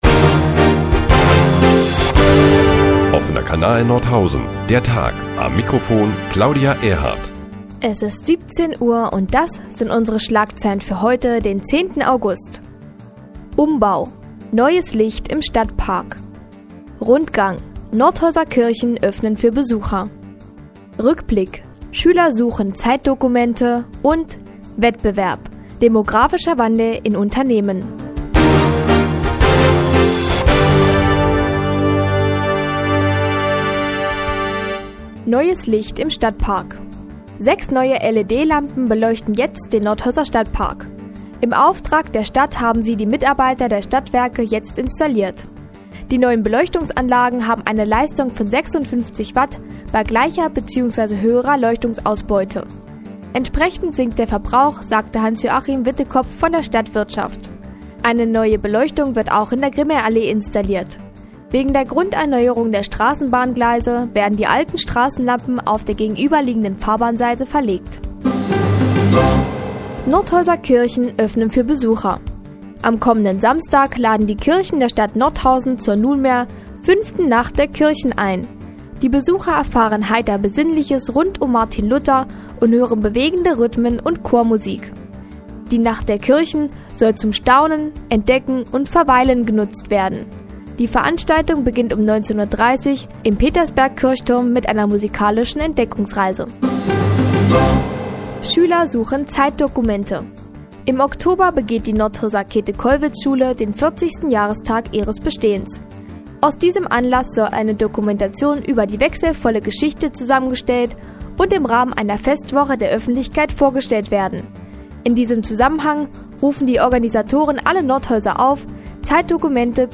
Die tägliche Nachrichtensendung des OKN ist nun auch in der nnz zu hören. Heute unter anderem der Besuchsmöglichkeit "Nacht der Kirchen" und dem demografischen Wandel in Unternehmen.